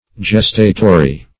Meaning of gestatory. gestatory synonyms, pronunciation, spelling and more from Free Dictionary.
Search Result for " gestatory" : The Collaborative International Dictionary of English v.0.48: Gestatory \Ges"ta*to*ry\, a. [L. gestatorius that serves for carrying: cf. F. gestatoire.]